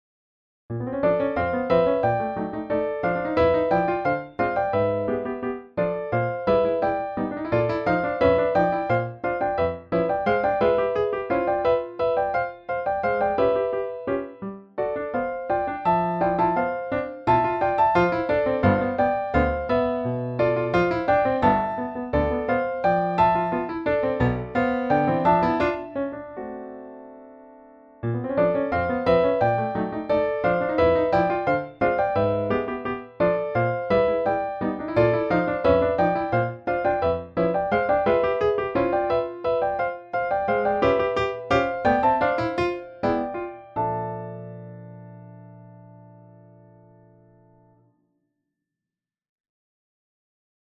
– Polka
Piano duet 1st part easy